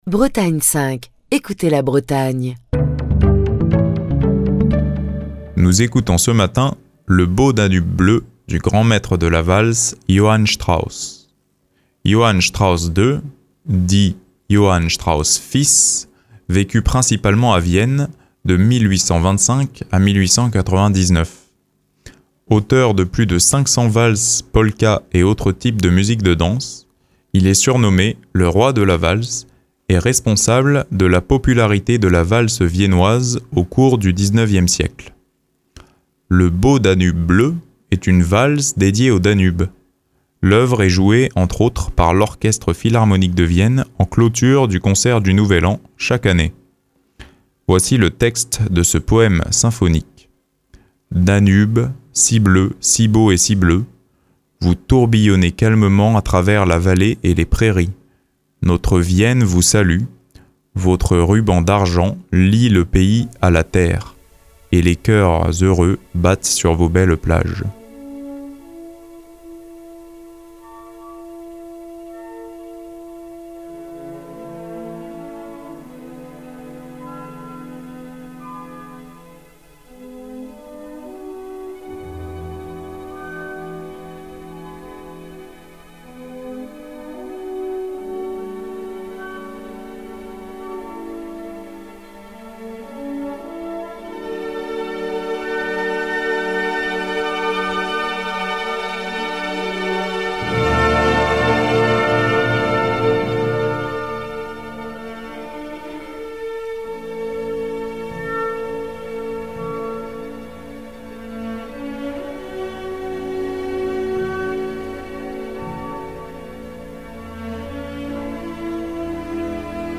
Le Beau Danube bleu est une valse dédiée au Danube.